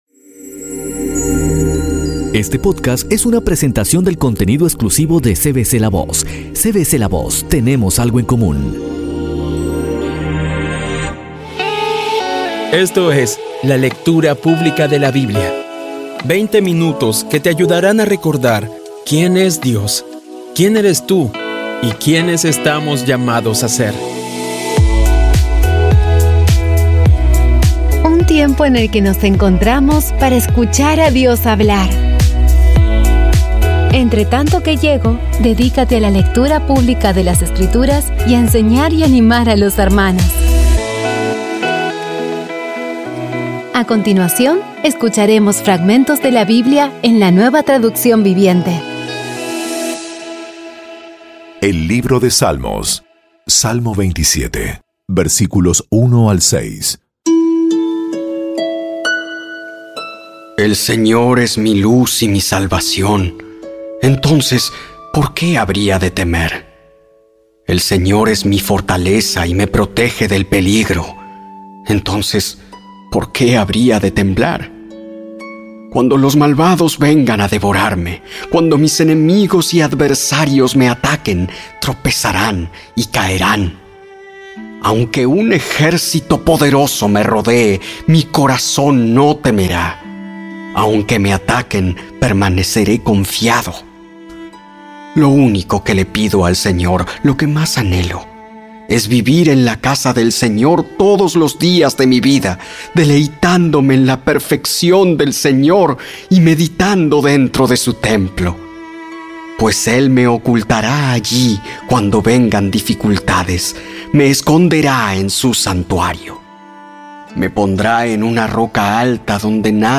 Con tan solo veinte minutos diarios, vas cumpliendo con tu propósito de estudiar la Biblia completa en un año. Poco a poco y con las maravillosas voces actuadas de los protagonistas vas degustando las palabras de esa guía que Dios nos dio.